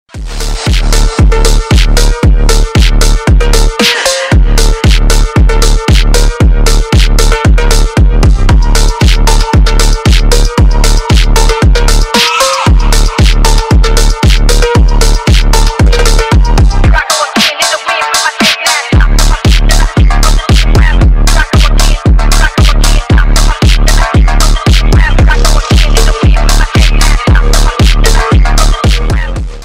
• Песня: Рингтон, нарезка
Громкая музыка для звонка